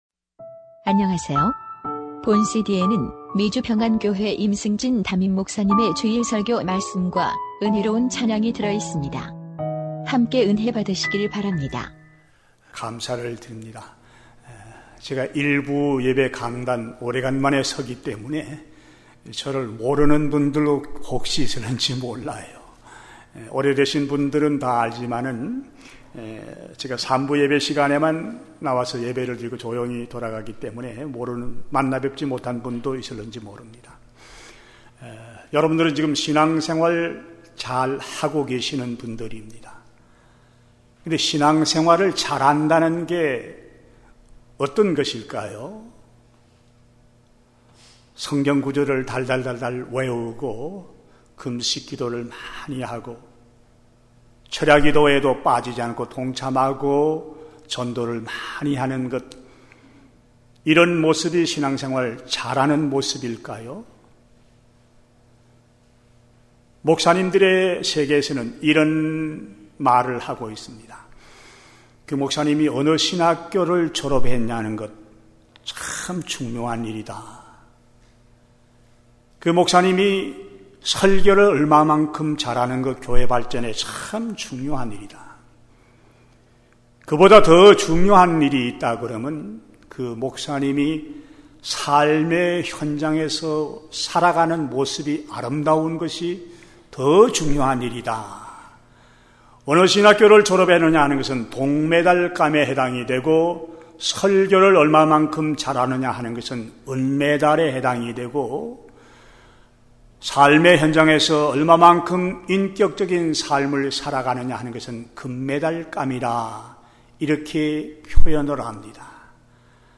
주일설교말씀: 그리스도의 생명을 가진자의 삶 (빌 2:1-11)